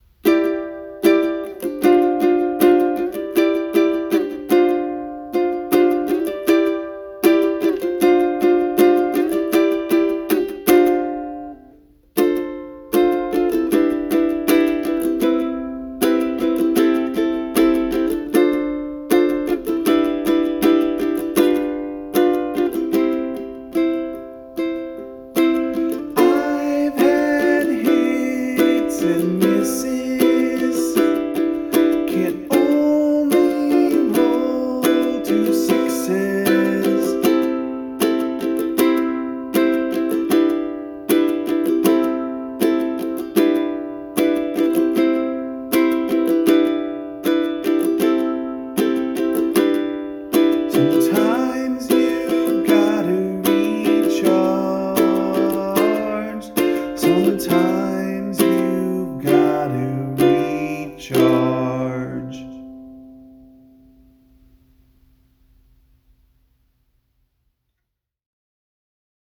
Musically, I decided to see what I could do with pretty much every chord here being either a major or a minor seventh. This was an interesting challenge since I was using a concert ukulele, which only has 4 strings (and has fairly limited range due to its re-entrant tuning).
I count this song in 2/4 time instead of 4/4.
I recorded this simply, using just my phone’s high-quality mode and doing both the ukulele and vocals live.